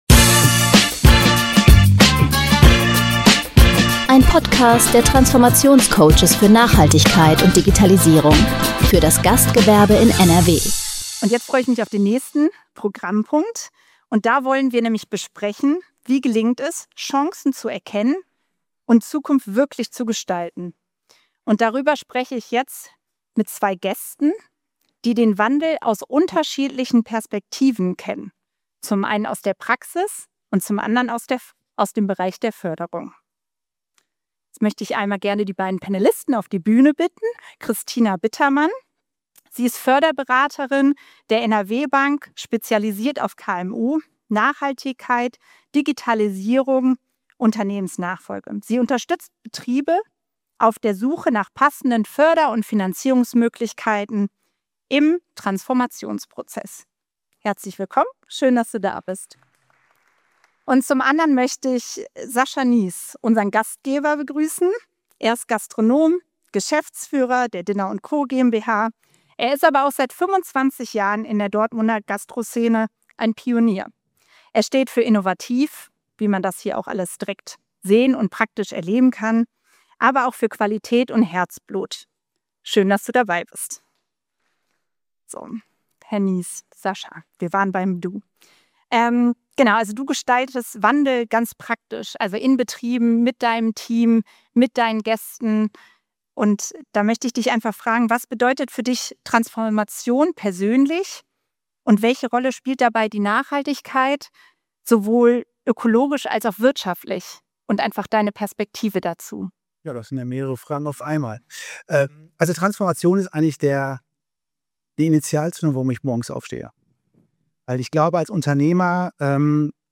Im Mitschnitt des Panels erhalten Sie Einblicke, wie sich Unternehmen in NRW zukunftsfähig aufstellen können – von Fördermöglichkeiten über konkrete Projektideen bis hin zu inspirierenden Impulsen, die Mut machen. Ob durch die IGA 2027 oder andere Bewegungen in NRW: Es geht um praktikable Wege, die das Gastgewerbe nachhaltig stärken.